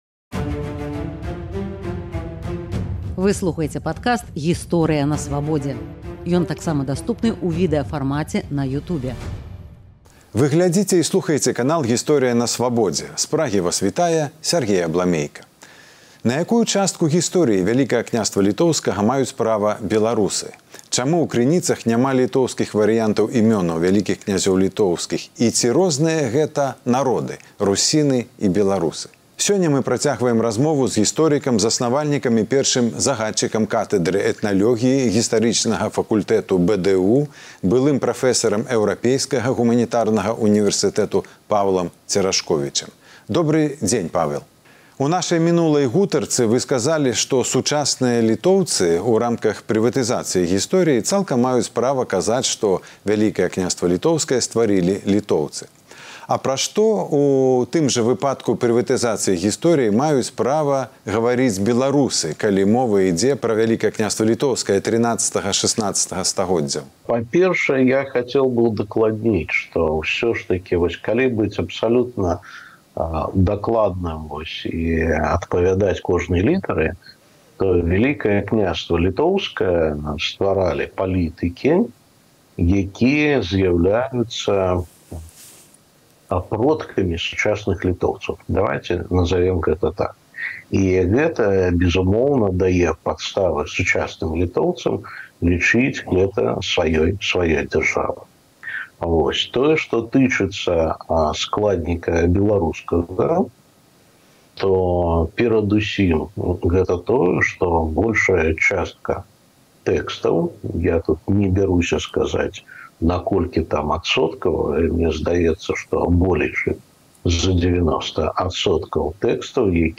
Сёньня мы працягваем размову з гісторыкам